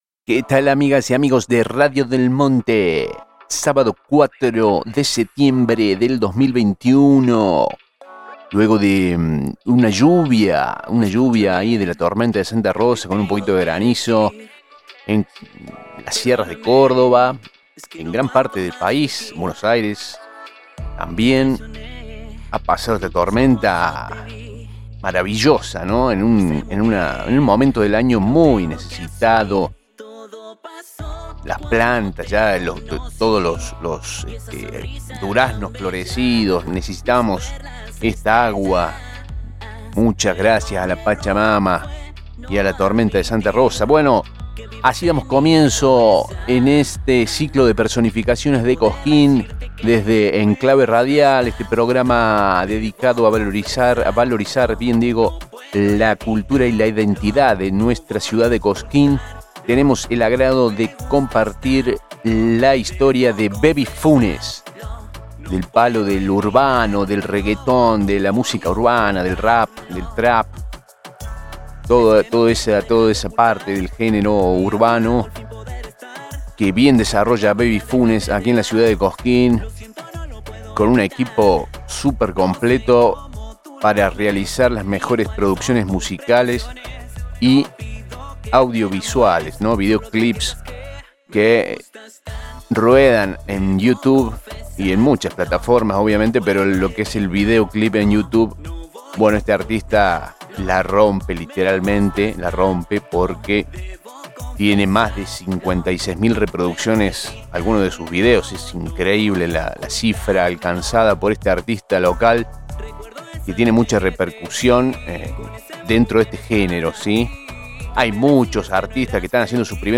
Aquí van a poder escuchar la entrevista completa